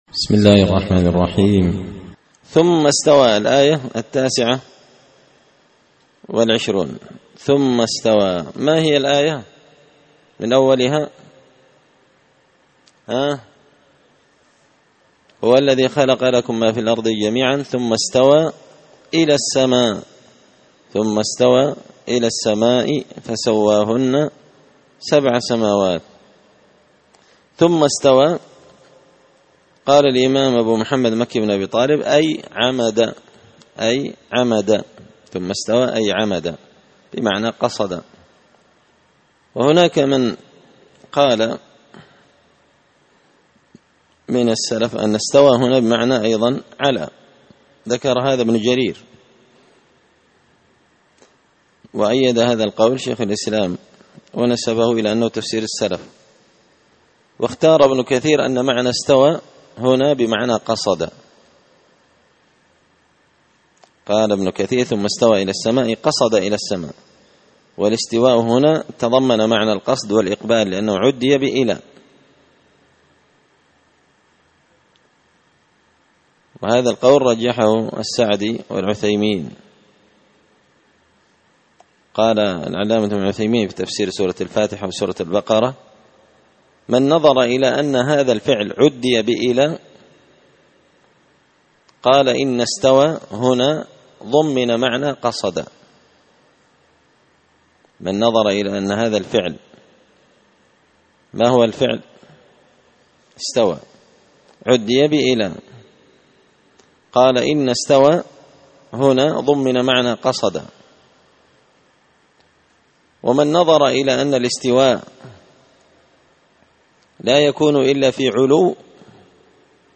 تفسير مشكل غريب القرآن ـ الدرس 8
دار الحديث بمسجد الفرقان ـ قشن ـ المهرة ـ اليمن